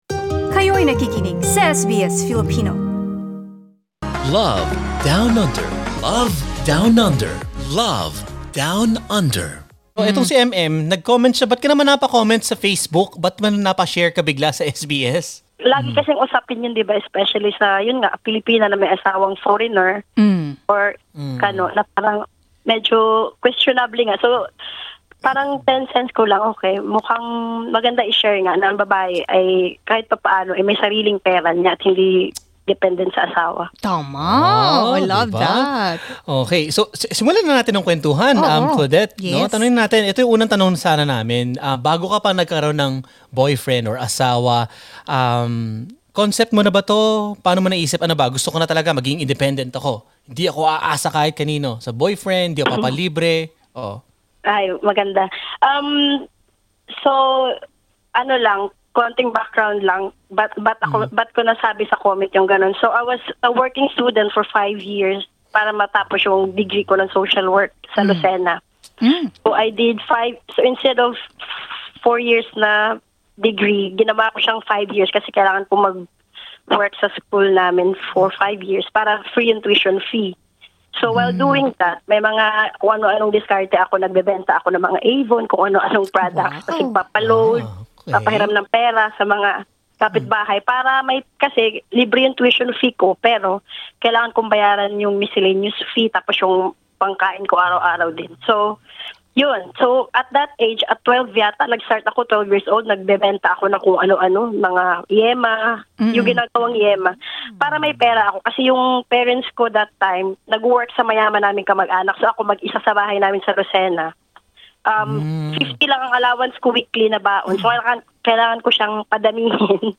Ipinaliwanag ng isang Filipina kung bakit nagpapadala pa rin siya ng pera sa kanyang pamilya. Pakinggan ang panayam.